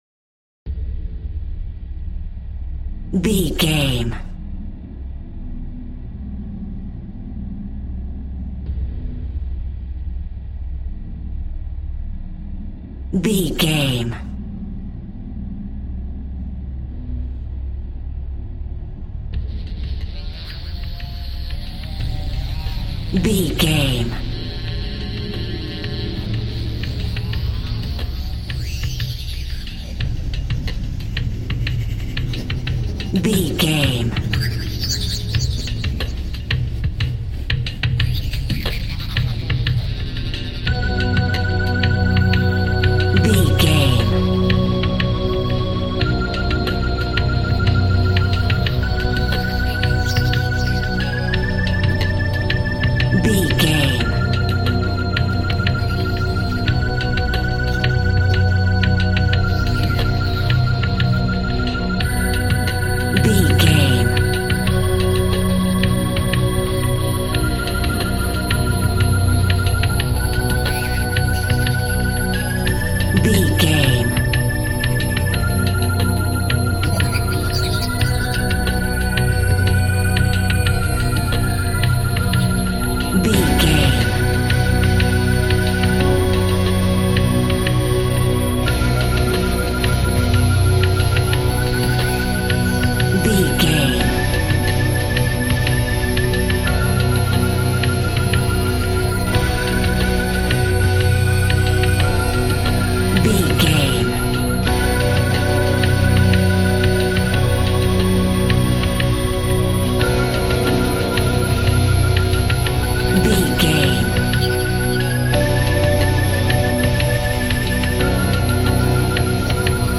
Ionian/Major
piano
synthesiser